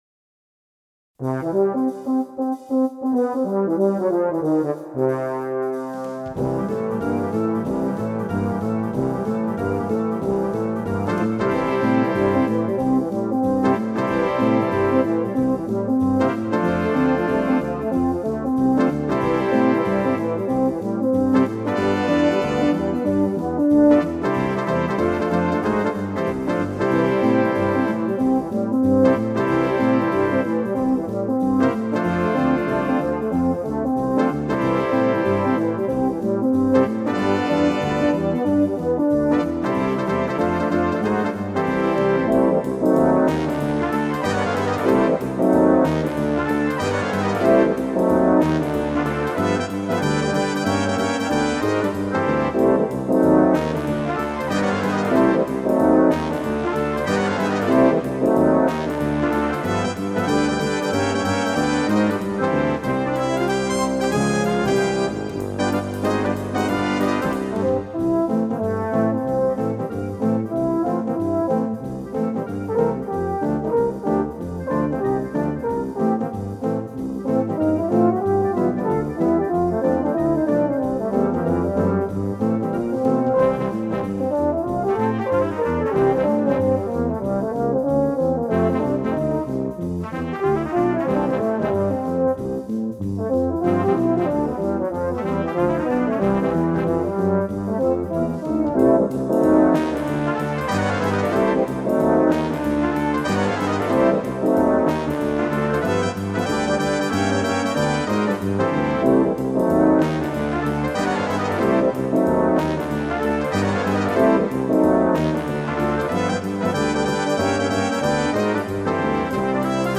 Tenorhorn-Parade
für kleines Blasorchester und 4 Tenorhorn/Bariton-Solisten